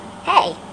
Hey (cartoon) Sound Effect
Download a high-quality hey (cartoon) sound effect.
hey-cartoon-2.mp3